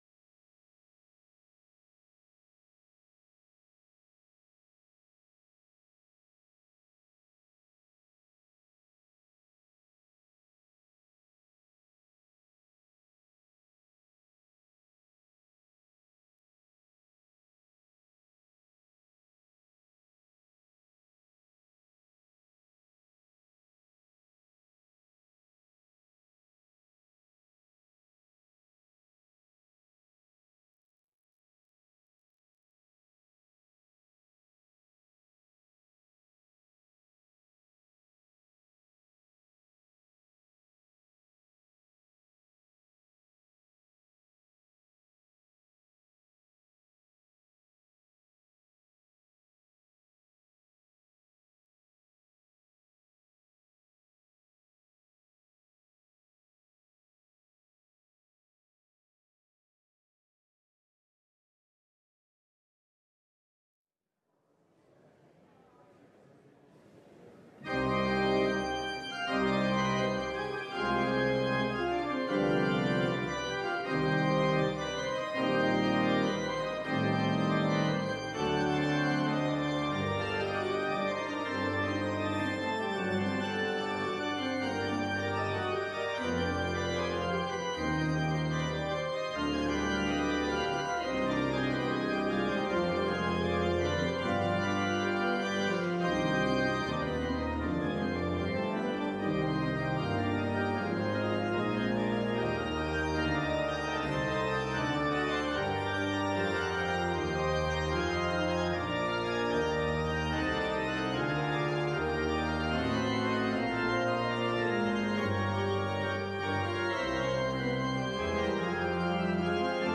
LIVE Morning Worship Service - Fulfilling the Law
Congregational singing—of both traditional hymns and newer ones—is typically supported by our pipe organ. Vocal choirs, handbell choirs, small ensembles, instrumentalists, and vocal soloists provide additional music offerings.